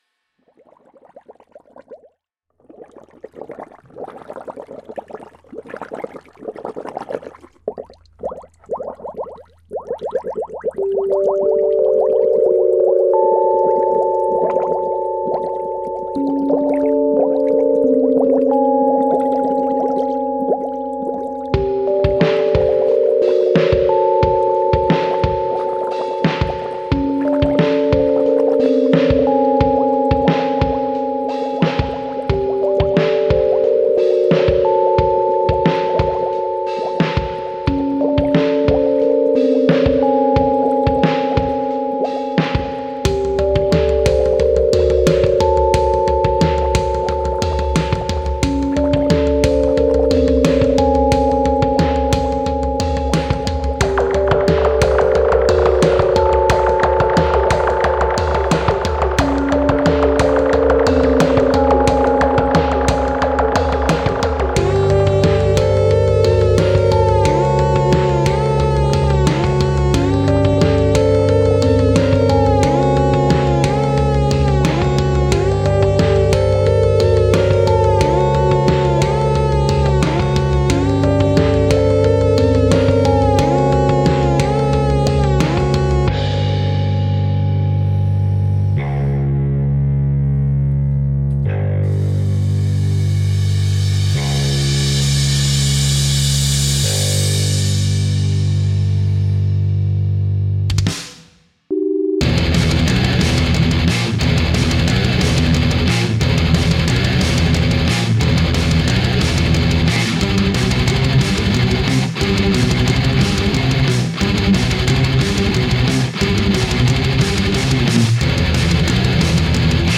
• Lead Guitar
• Programming